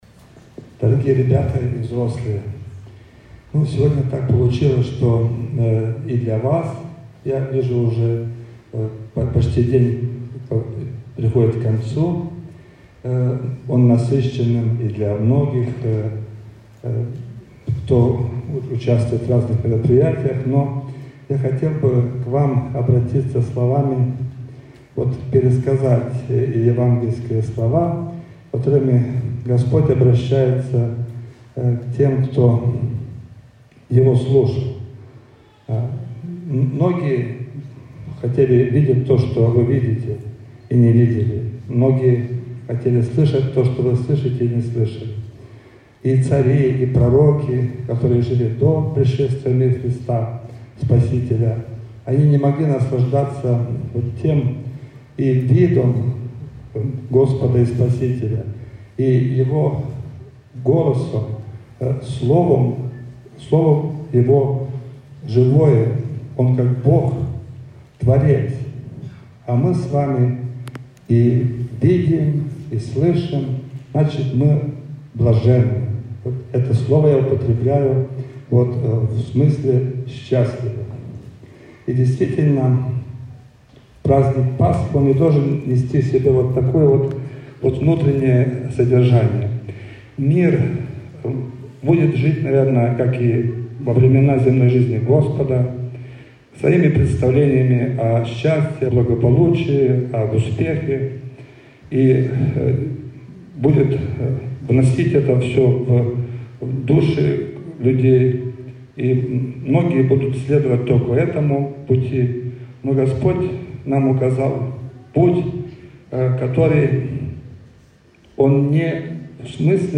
25 апреля 2025 года в Красносельском благочинии по сложившейся многолетней традиции прошёл гала-концерт «Пасха Красная», в ходе которого состоялась церемония награждения участников одноимённого фестиваля-конкурса.
Праздник проходил в концертном зале Дома детского творчества Красносельского района.
Учащиеся образовательных учреждений района представили конкурсные работы в различных номинациях: художественный рисунок, декоративно-прикладное искусство, вокально-хоровое искусство, художественное слово, инсценировка.
Гала-концерт.mp3